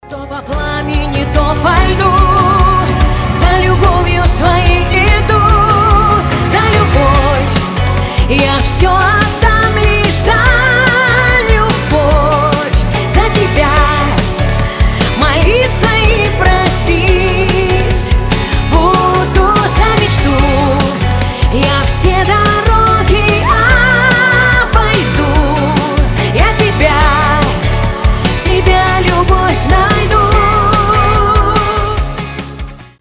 • Качество: 320, Stereo
поп
громкие